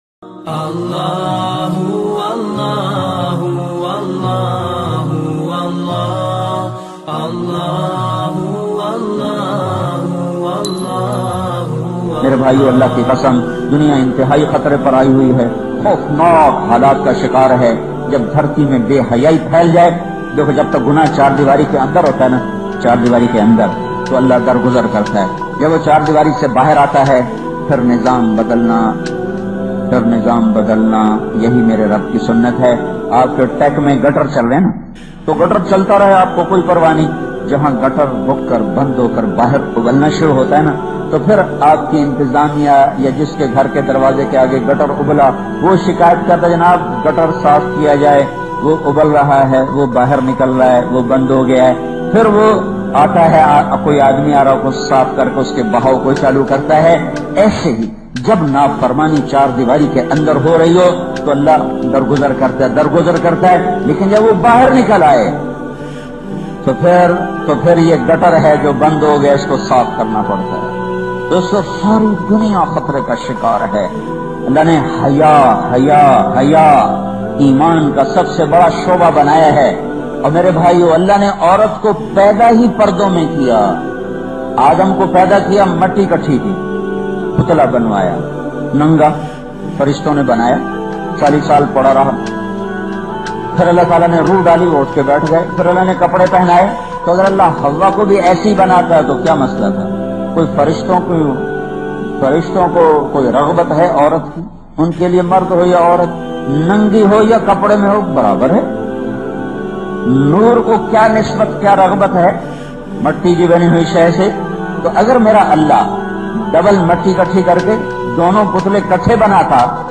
Valentine Day very Emotional Bayan by Maulana Tariq Jameel